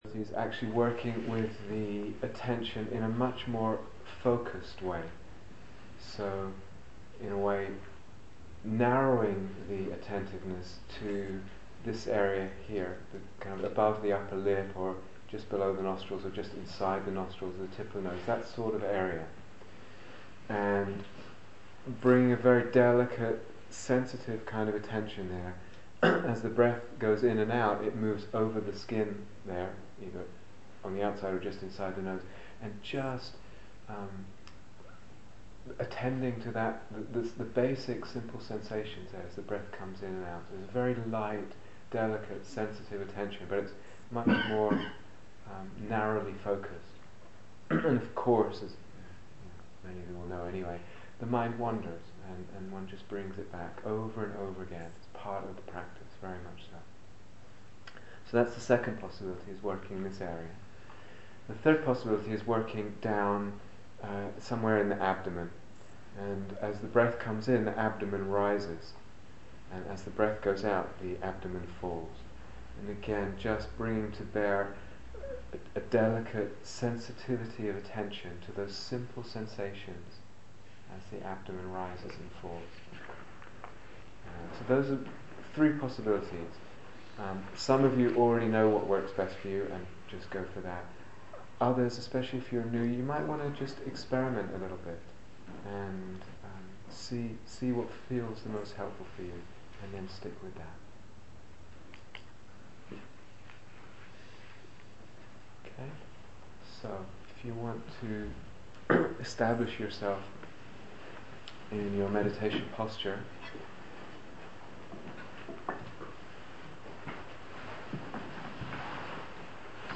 Instructions and Short Guided Meditation (Breath)
Retreat/SeriesCambridge Day Retreats 2008